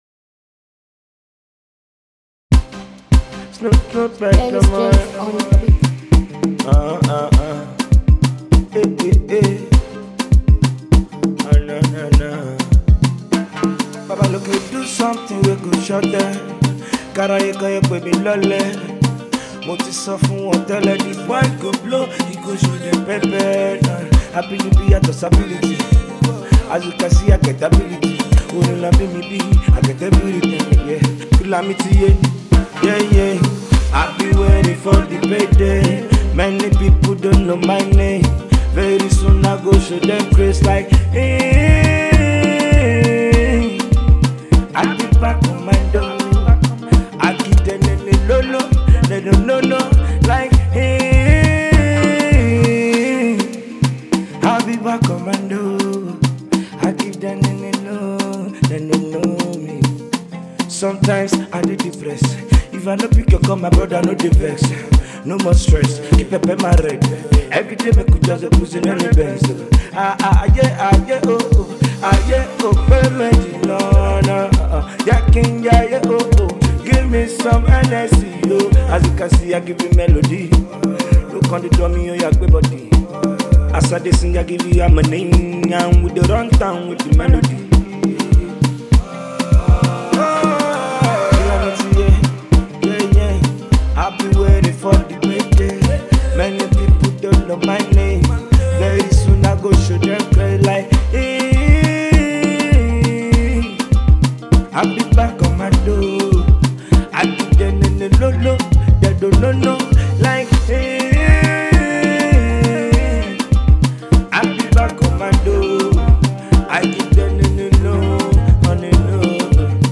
dancehall jam
set you in a dancing mood